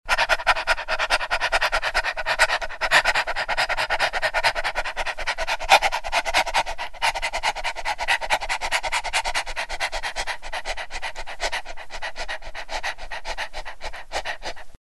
Звуки собак
Звук тяжелого дыхания собаки после быстрого бега